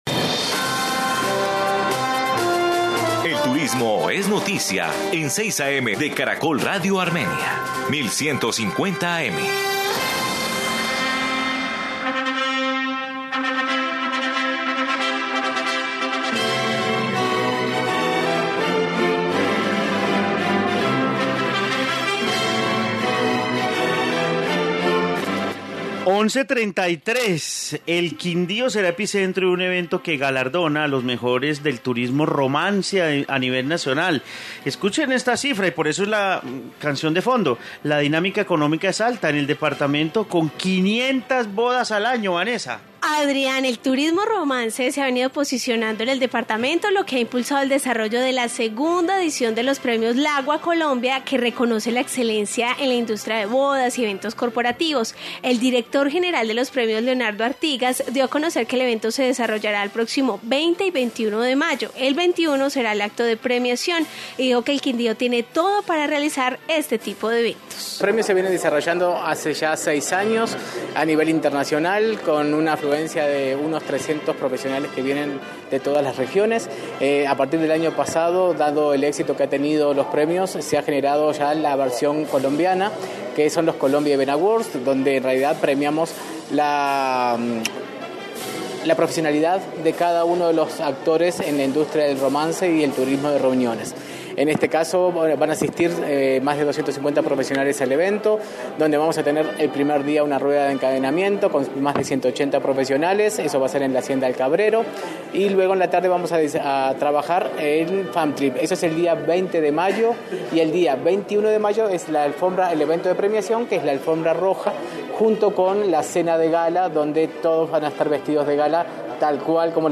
Informe turismo romance